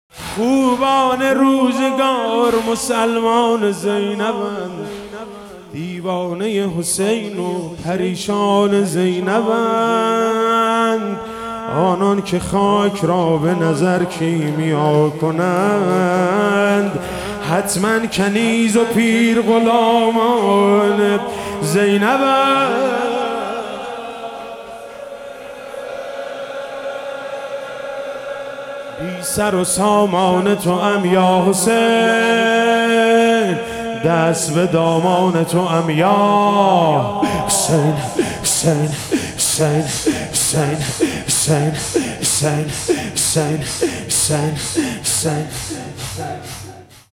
شب شام غریبان محرم 97 - واحد - خوبان روزگار مسلمان زینبند